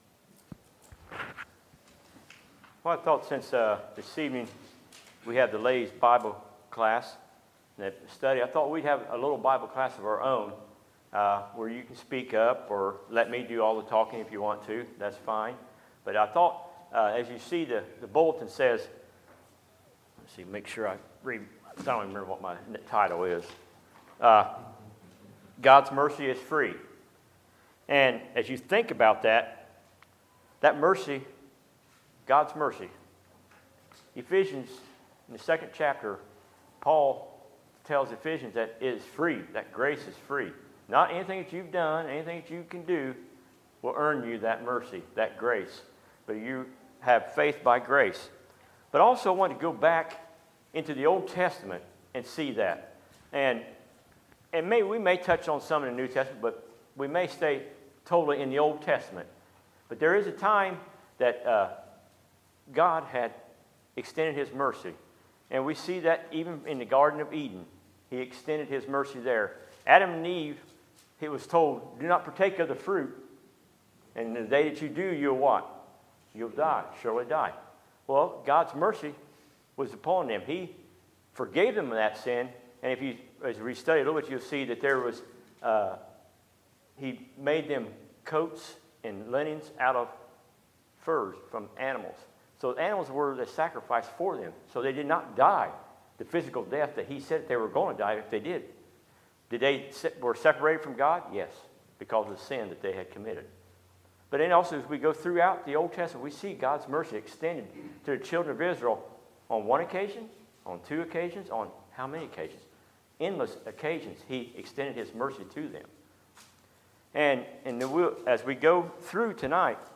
Sermons, April 1, 2018